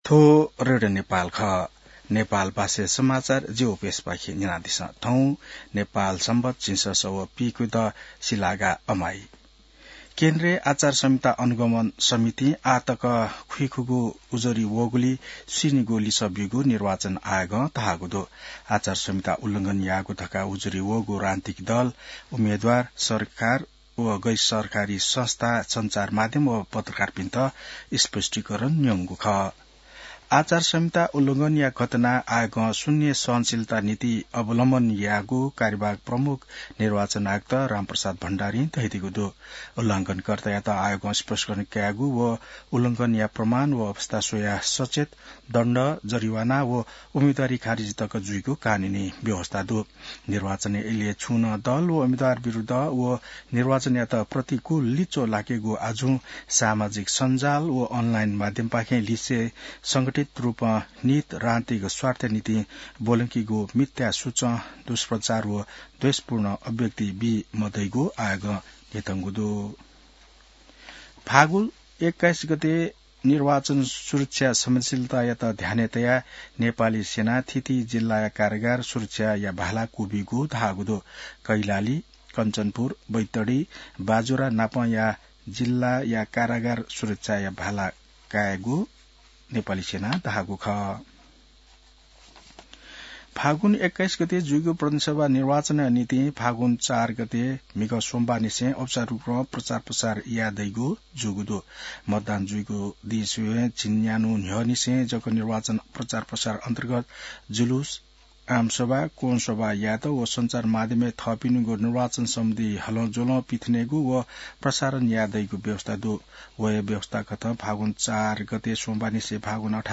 नेपाल भाषामा समाचार : ५ फागुन , २०८२